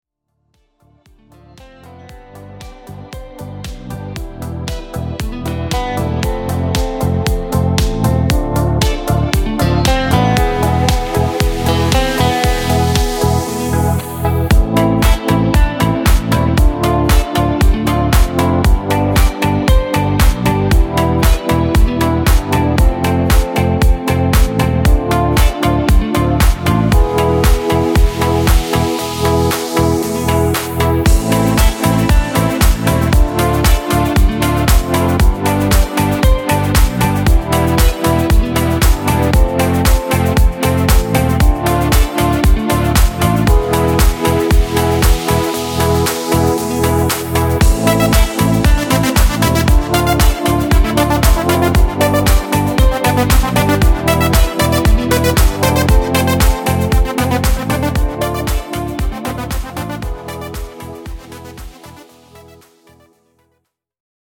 Dance